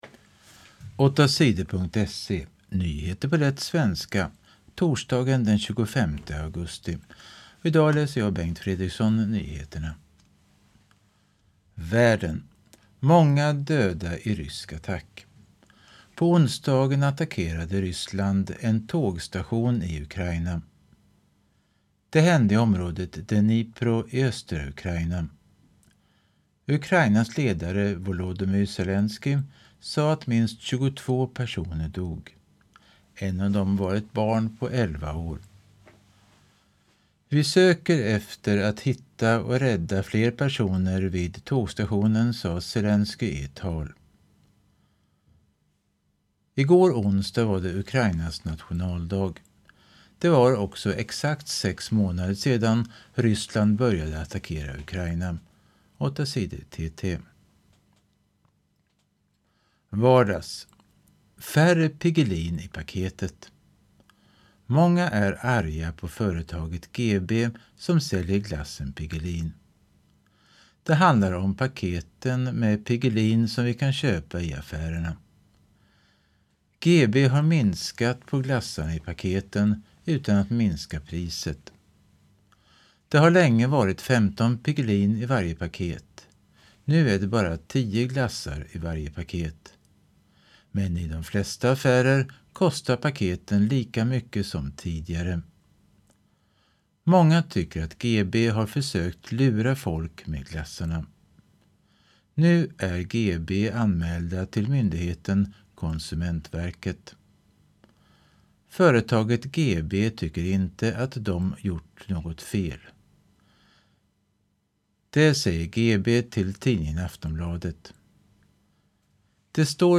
Nyheter på lätt svenska den 25 augusti